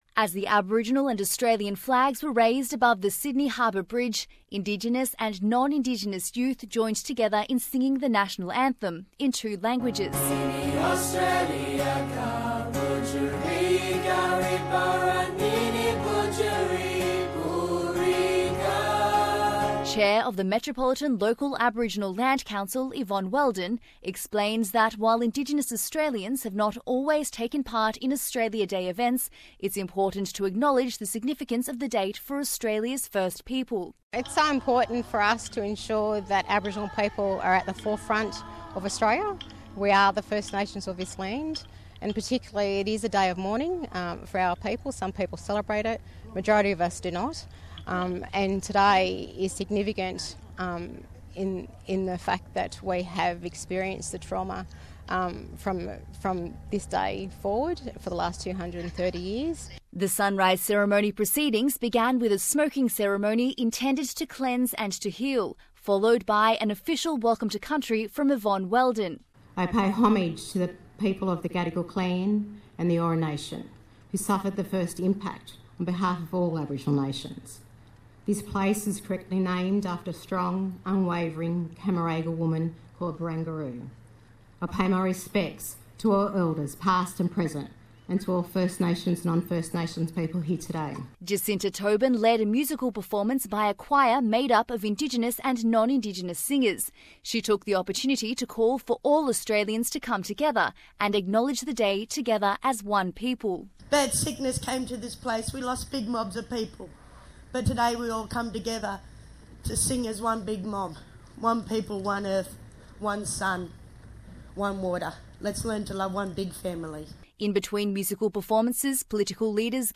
Indigenous Australians have taken part in traditional and in-language performances, sharing what January 26th means to the country's first nations.